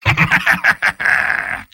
Robot-filtered lines from MvM. This is an audio clip from the game Team Fortress 2 .
Soldier_mvm_laughevil01.mp3